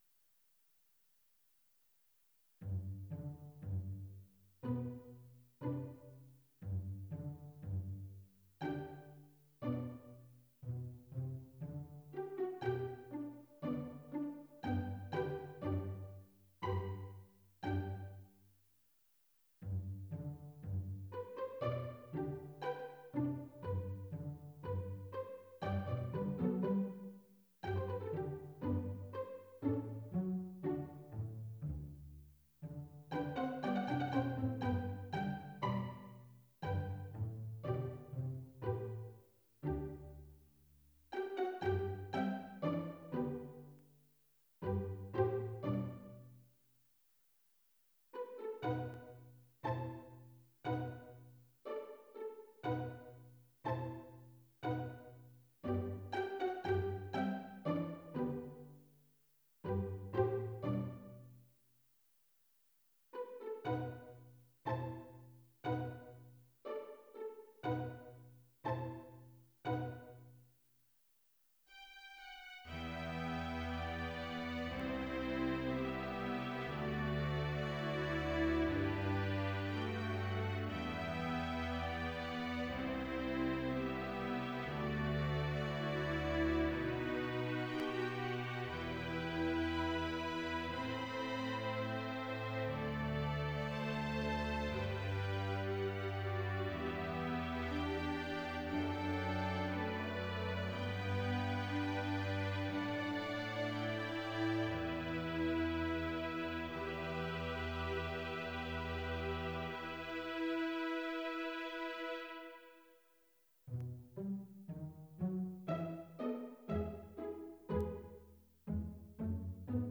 It's kind of fun; for some reason, it reminded me of my cats.
:) mp3 download wav download Files: wav mp3 Tags: Quartet, Strings Plays: 1918 Likes: 0